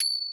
Key-rythm_ching_04.wav